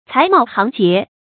材茂行洁发音